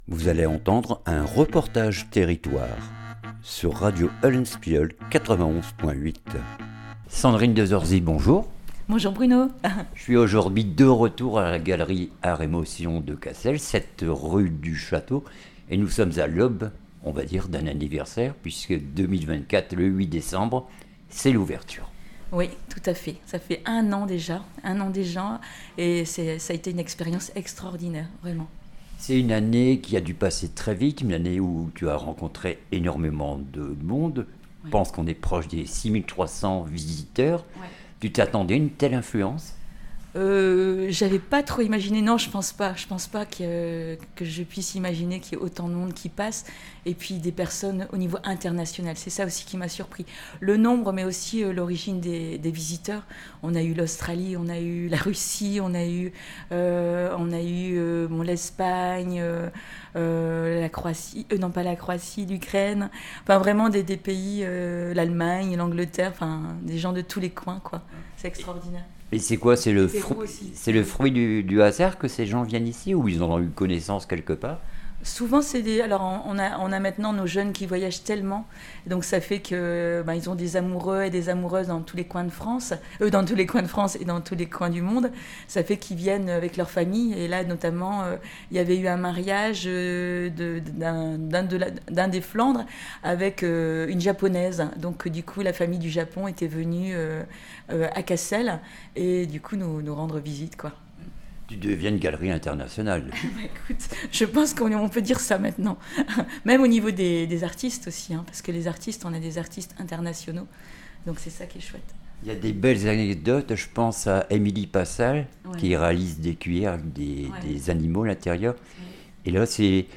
REPORTAGE TERRITOIRE ART & MOTION CASSEL 1 AN !